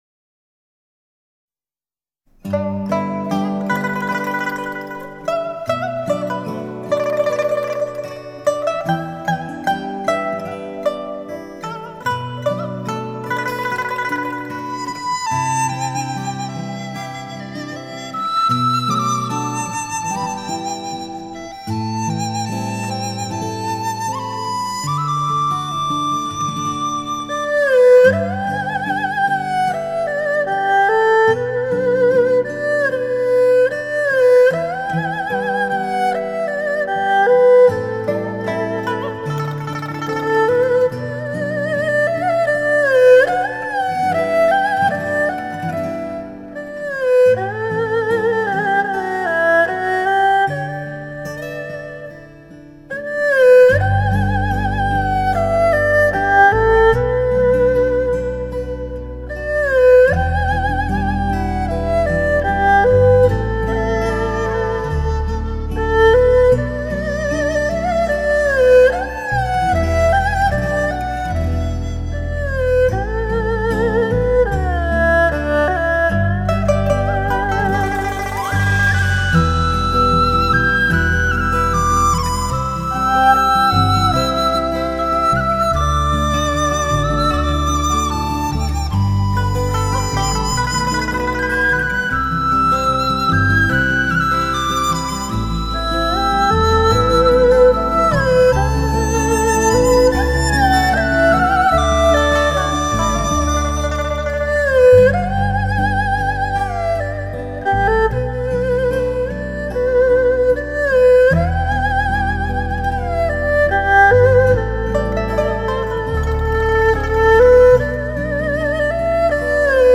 背景音乐一